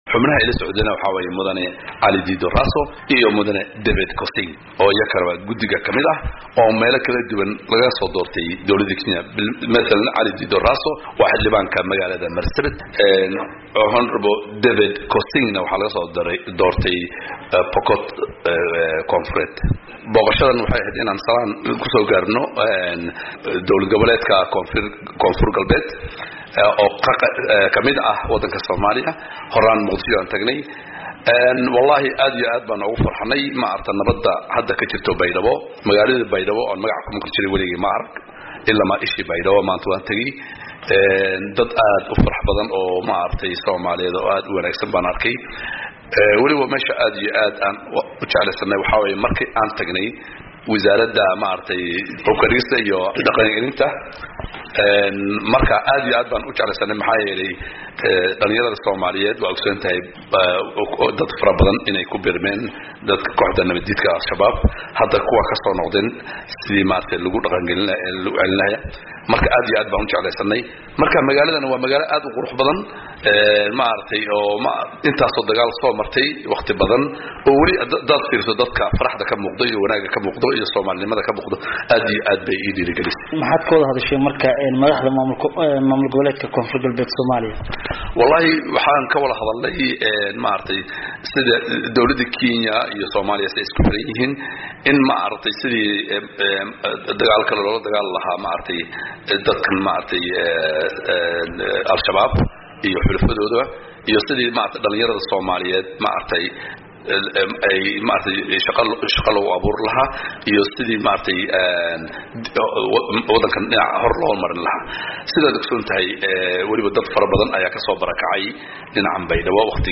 Wareysi: Xildhibaan Ilyaas Barre